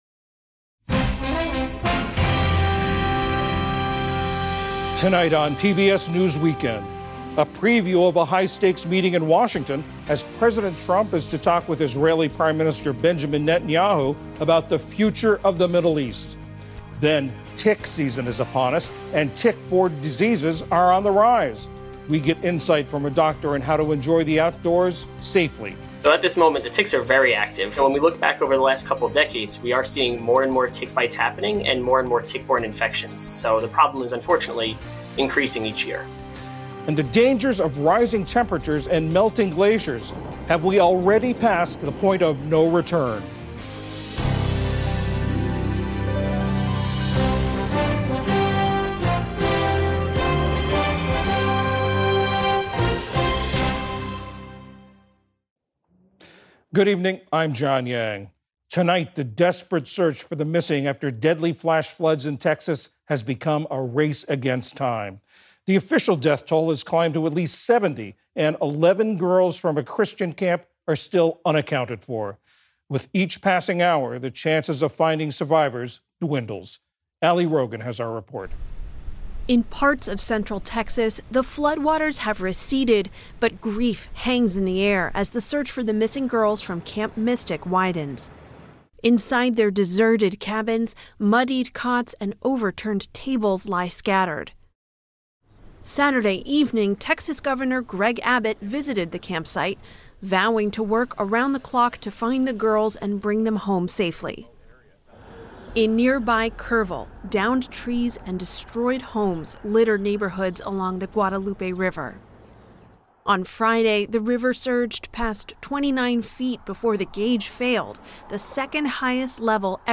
Daily News